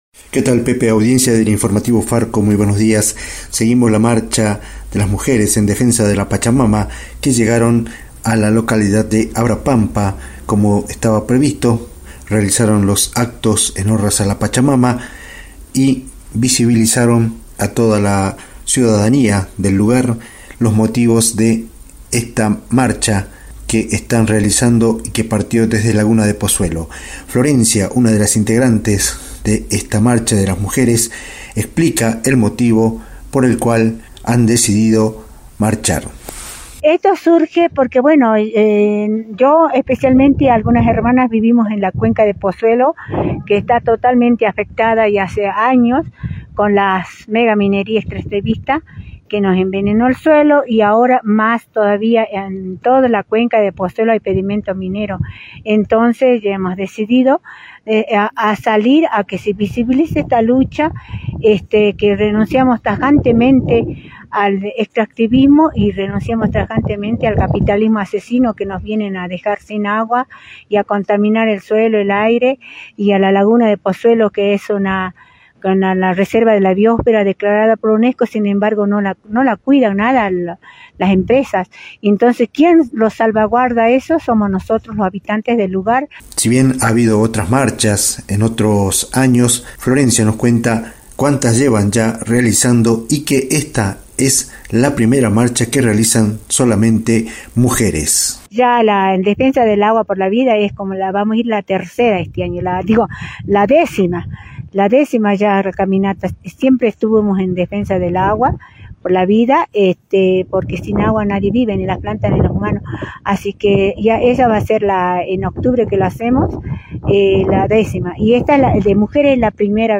REPORTE-LA-VOZ-DEL-CERRO-Caminata-de-mujeres-originarias.mp3